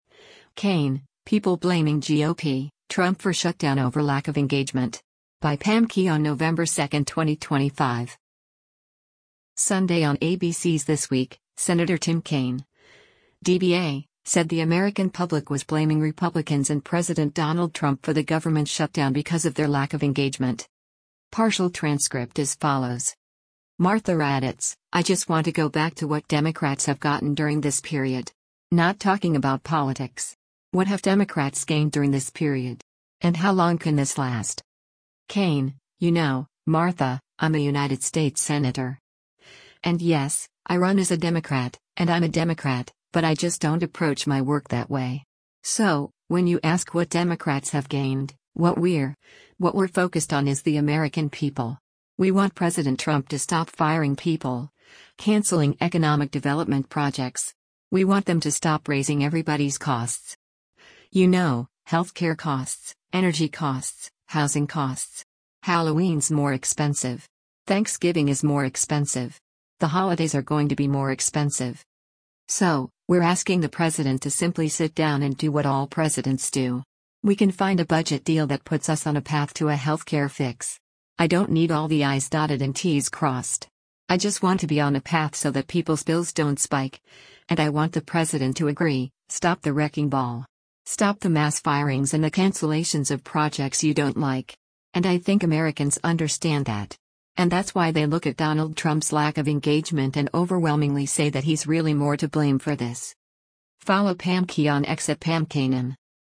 Sunday on ABC’s “This Week,” Sen. Tim Kaine (D-VA) said the American public was blaming Republicans and President Donald Trump for the government shutdown because of their “lack of engagement.”